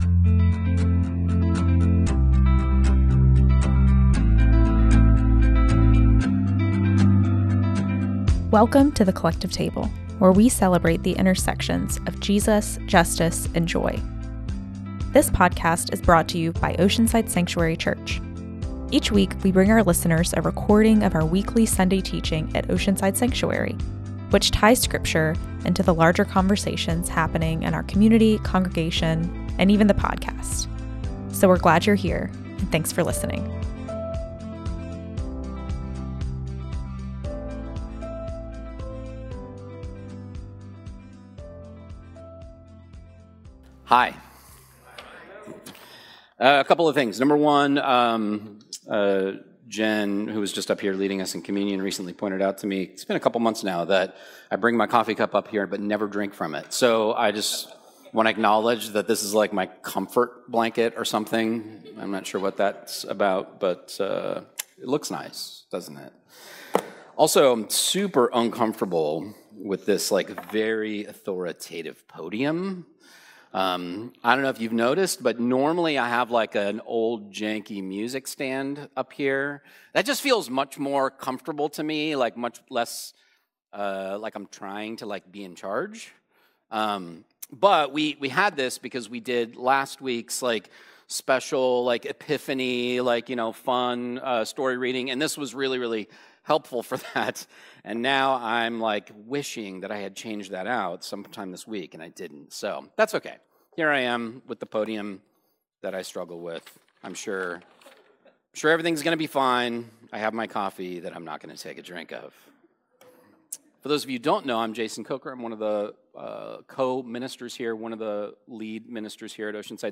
Each week, we bring our listeners a recording of our weekly Sunday teaching at Oceanside Sanctuary, which ties scripture into the larger conversations happening in our community, congregation and podcast.